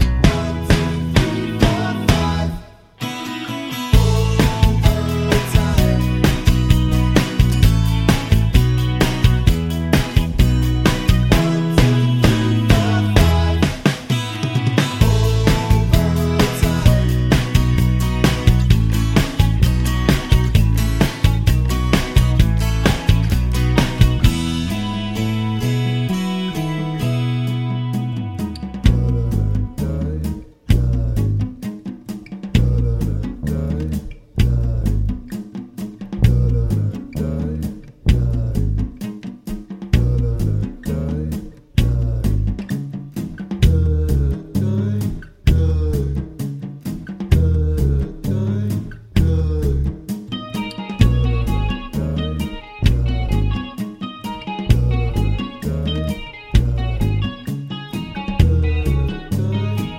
Pop (1980s)